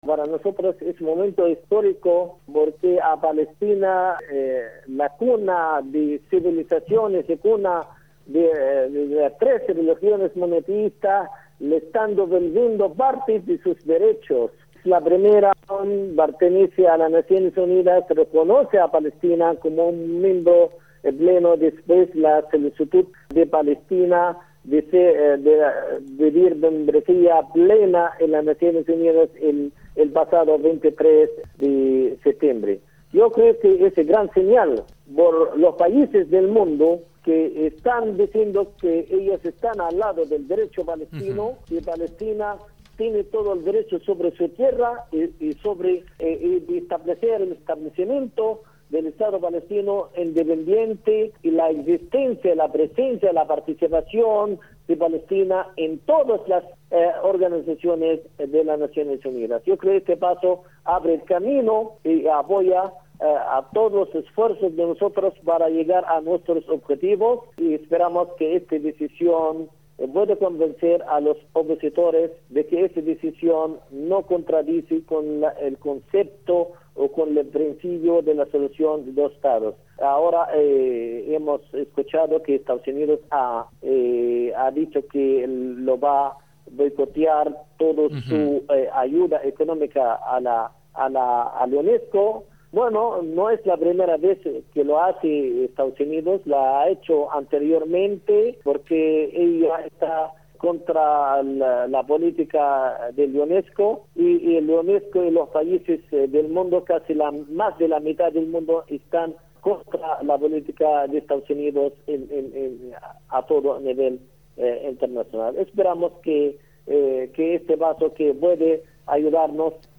Walid Muaqqat, embajador de Palestina en Argentina